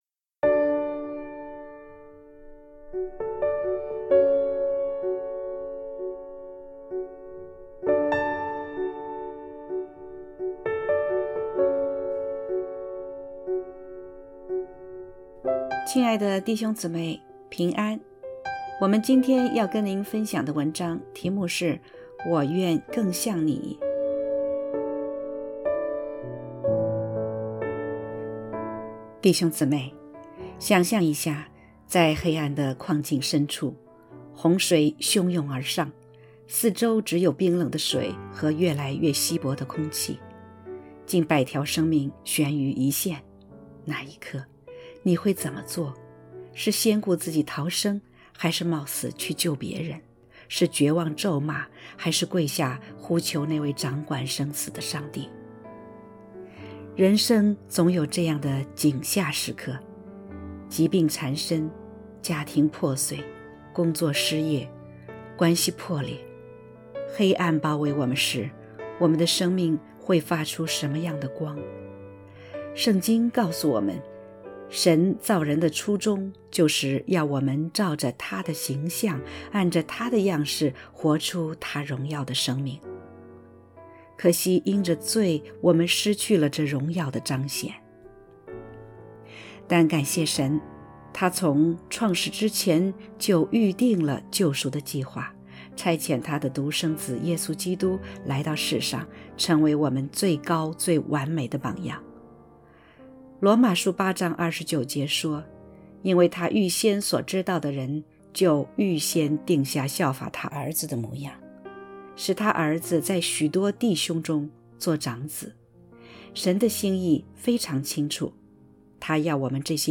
（合成）W我愿更像你【效法基督】.mp3